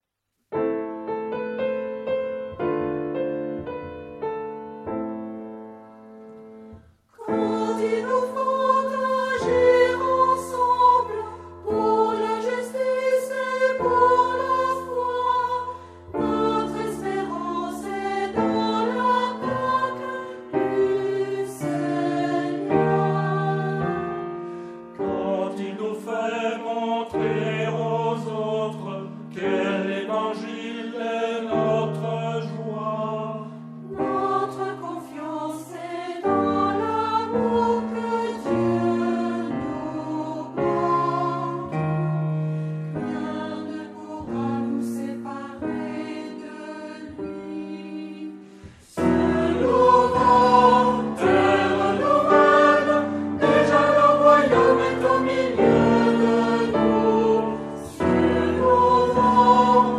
Genre-Style-Form: Sacred ; Hymn (sacred)
Mood of the piece: lively
Type of Choir: unisson
Instruments: Organ (1)
Tonality: A minor